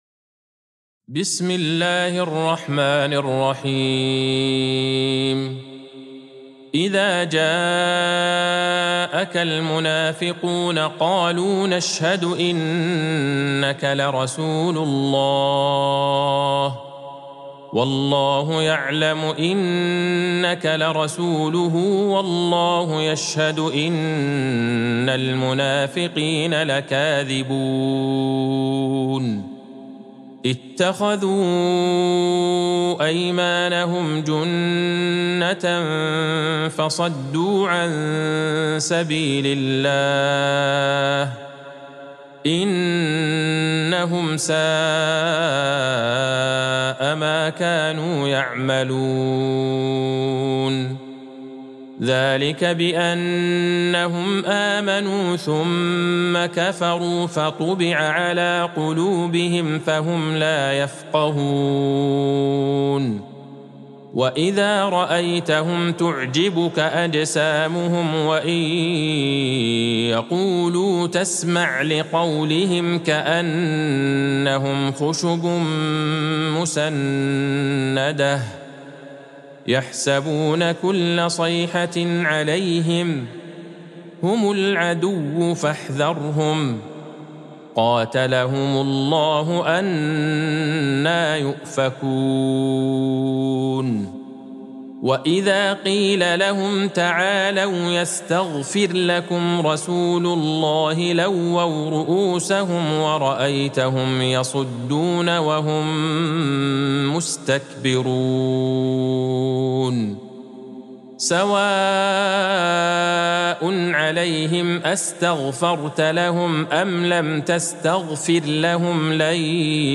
سورة المنافقون Surat Al-Munafiqun | مصحف المقارئ القرآنية > الختمة المرتلة ( مصحف المقارئ القرآنية) للشيخ عبدالله البعيجان > المصحف - تلاوات الحرمين